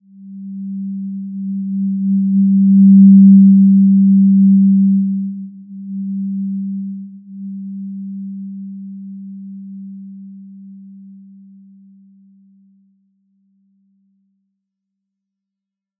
Simple-Glow-G3-p.wav